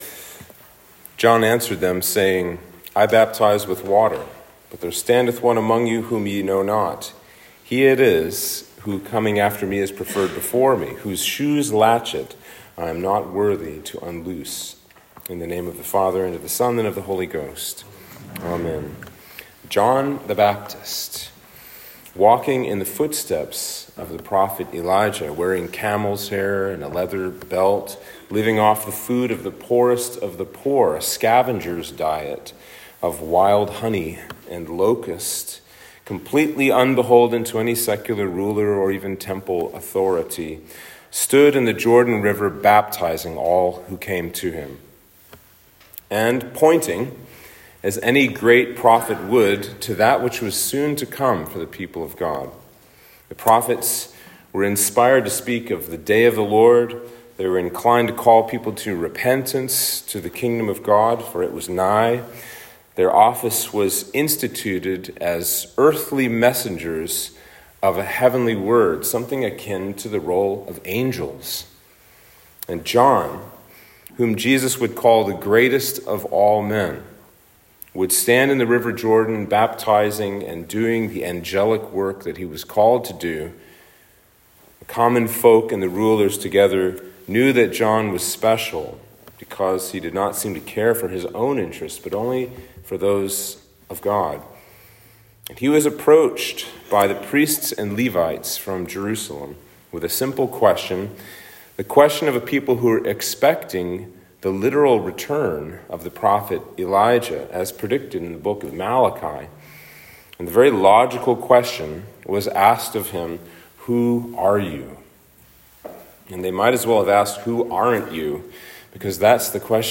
Sermon for Advent 4